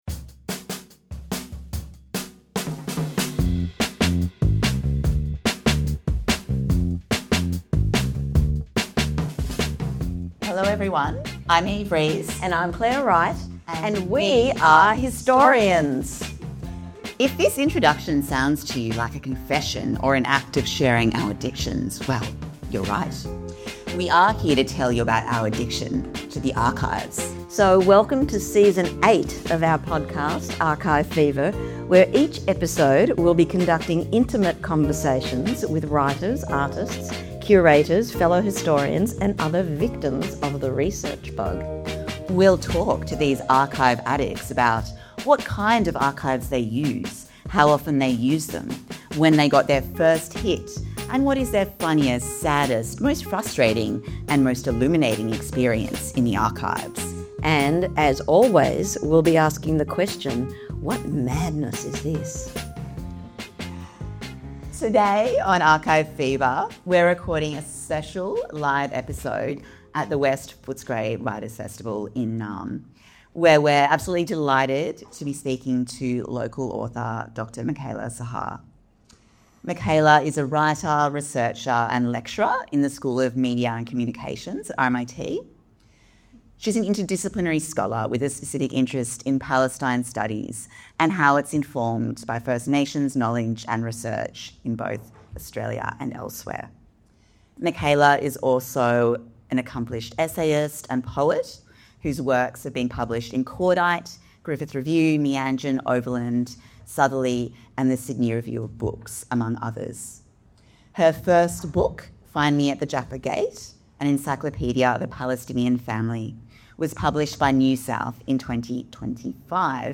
58 | My Grandfather's Key (Live at Footscray West Writers Fest)